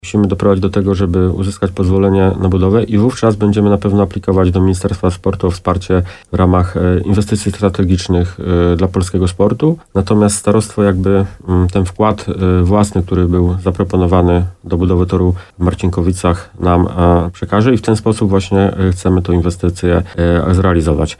Natomiast starostwo powiatowe przekaże nam ten wkład własny, który był zaproponowany do budowy toru w Marcinkowicach i w ten sposób tę inwestycję chcemy wykonać – komentuje burmistrz Piwnicznej-Zdroju, Tomasz Michałowski.